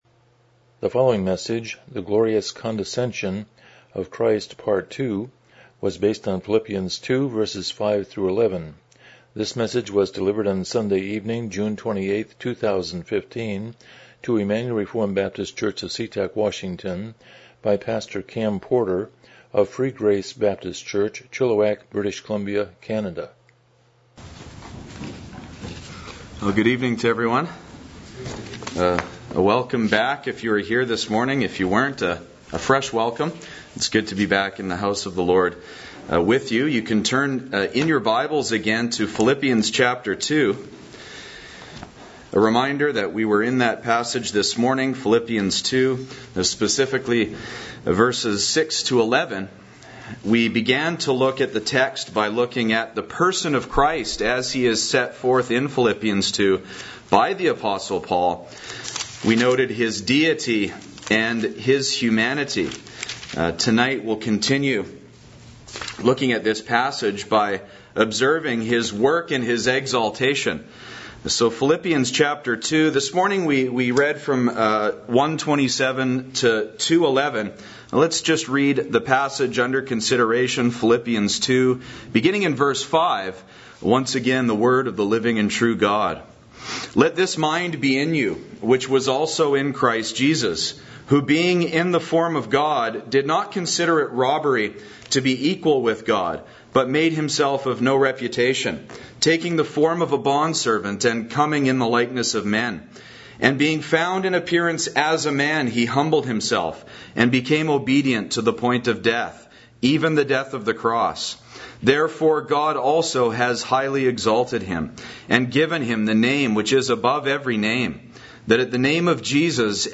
Miscellaneous Service Type: Evening Worship « The Glorious Condescension of Christ